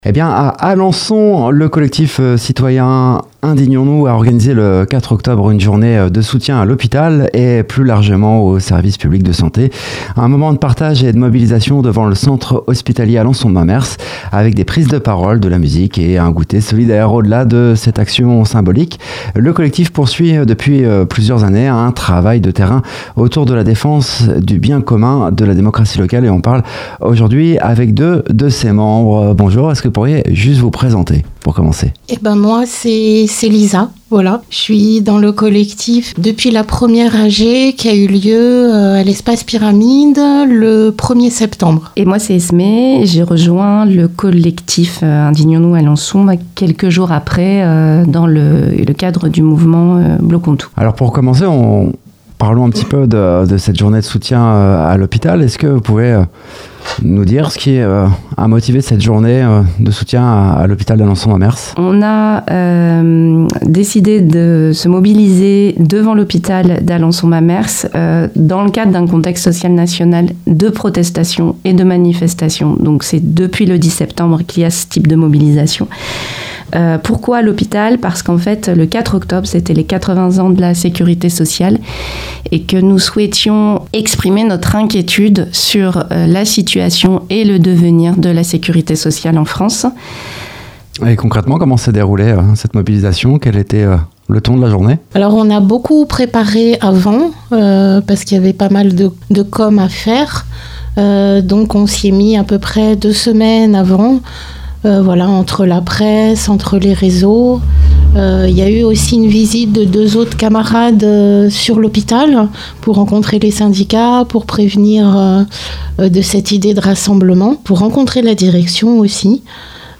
Dans cette interview, deux membres du collectif reviennent sur les raisons de cette mobilisation, l’état préoccupant de l’hôpital public, mais aussi sur le sens de leur engagement citoyen au service du bien commun. Ils évoquent les actions menées depuis plusieurs années, leur fonctionnement collectif et leur volonté de recréer du lien social et démocratique à l’échelle locale.